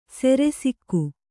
♪ sere sikku